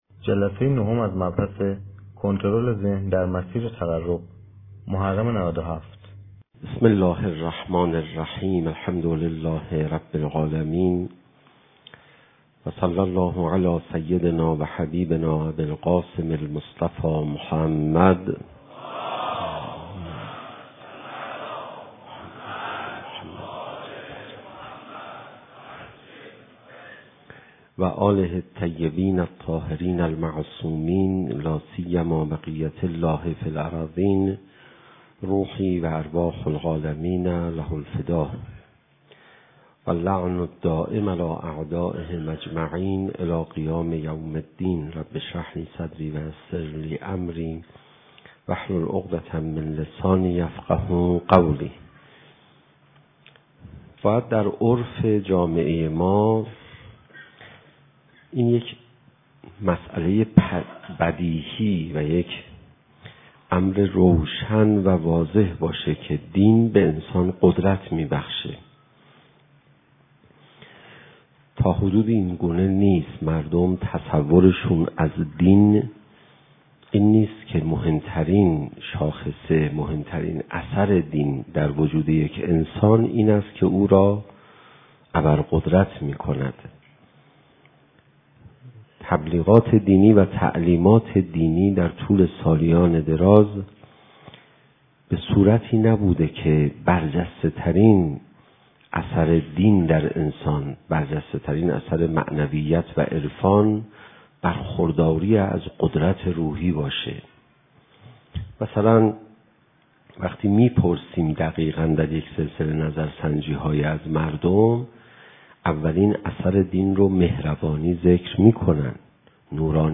سخنرانی پناهیان-کنترل ذهن :: پایگاه مذهبی شهید حججی